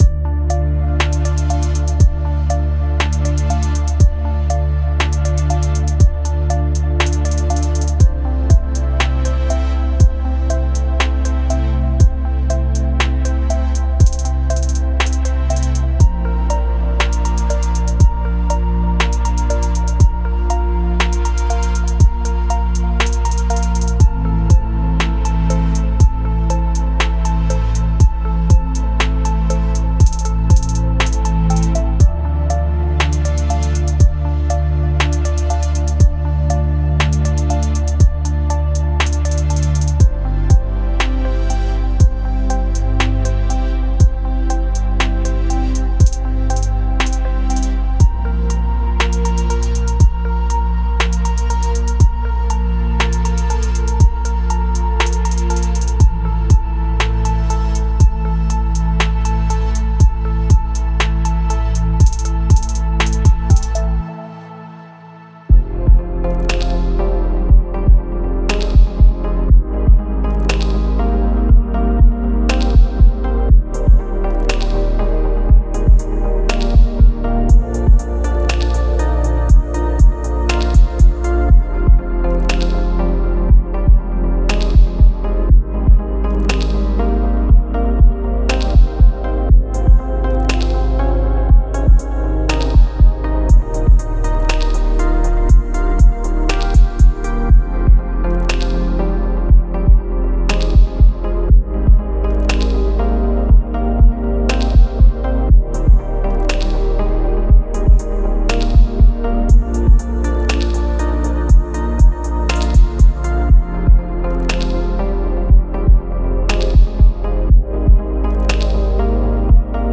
Ambient Chill Out / Lounge